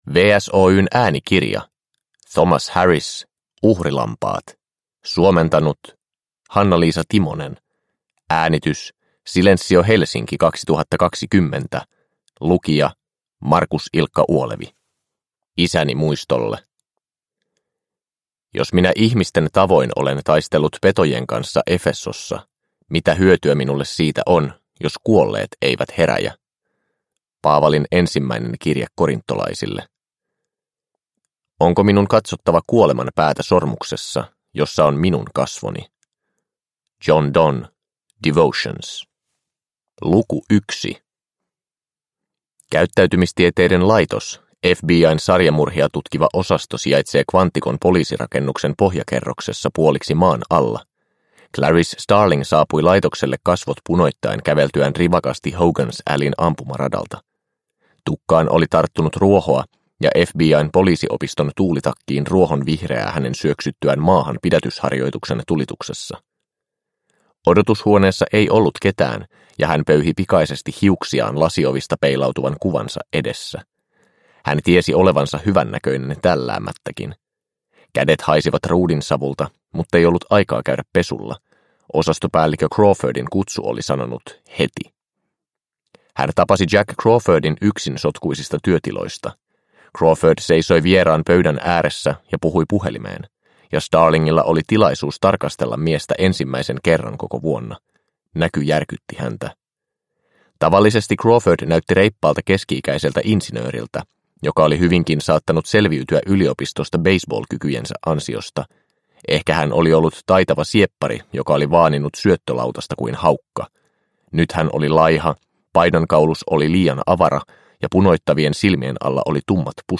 Uhrilampaat – Ljudbok – Laddas ner